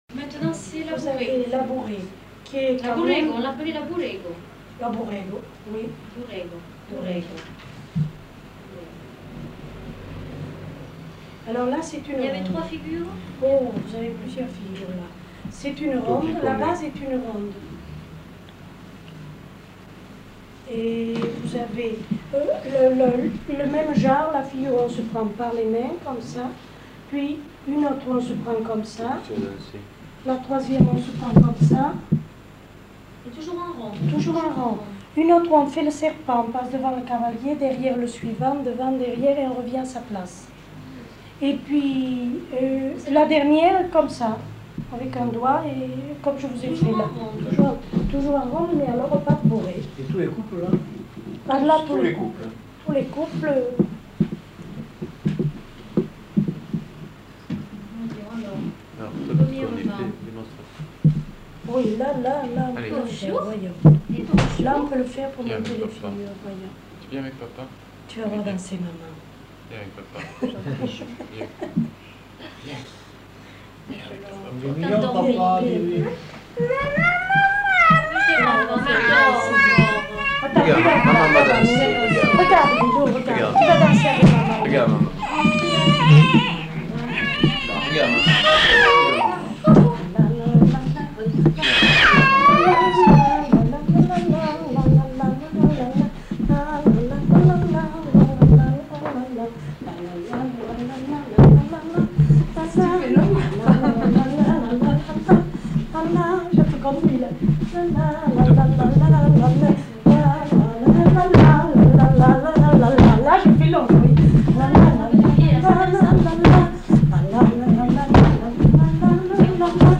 Description de la bourrée (avec air fredonné)